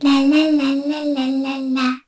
Kara-Vox_Hum_kr.wav